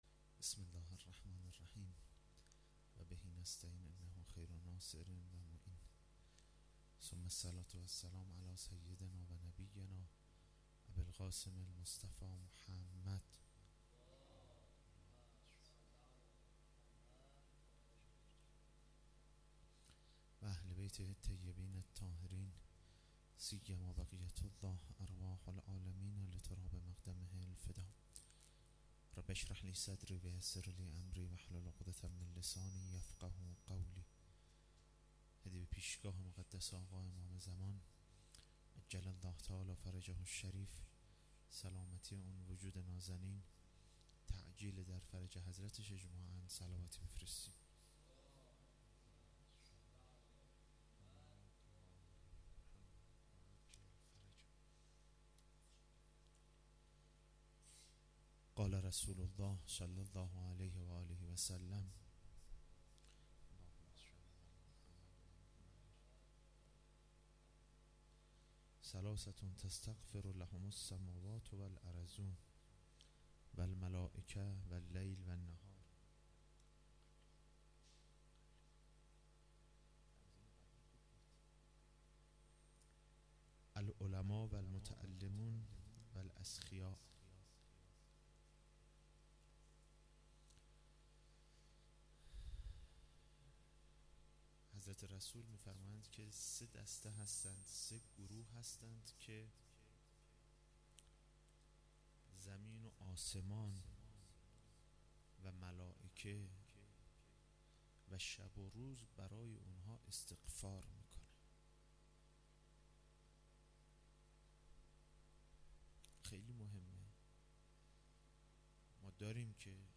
شب هشتم ماه رمضان 1392- هیات لثارات الحسین(ع) - حوزه علمیه نخبگان
sokhanrani.mp3